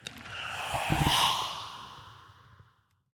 ambient_ominous5.ogg